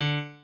pianoadrib1_8.ogg